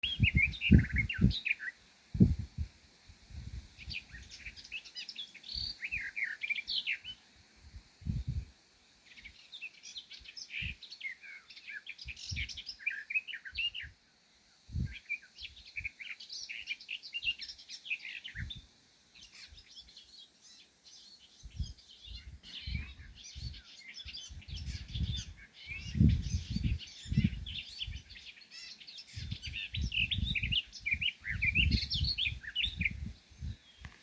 Birds -> Warblers ->
Garden Warbler, Sylvia borin
StatusSinging male in breeding season